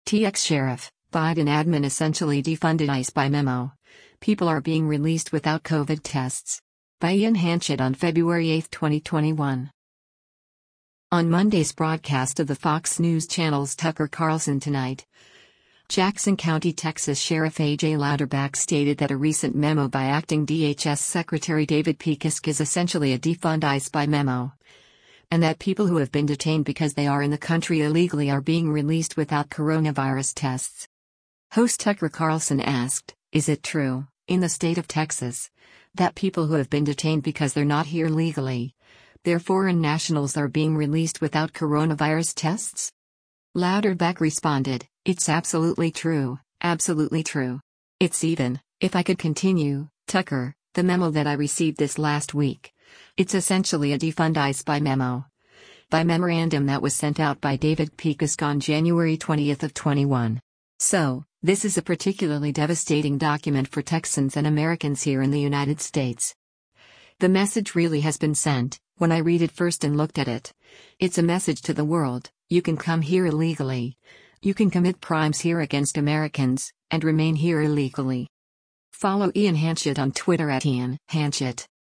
On Monday’s broadcast of the Fox News Channel’s “Tucker Carlson Tonight,” Jackson County, TX Sheriff A.J. Louderback stated that a recent memo by acting DHS Secretary David Pekoske is “essentially a defund ICE by memo,” and that people who have been detained because they are in the country illegally are being released without coronavirus tests.
Host Tucker Carlson asked, “Is it true, in the state of Texas, that people who have been detained because they’re not here legally, they’re foreign nationals are being released without coronavirus tests?”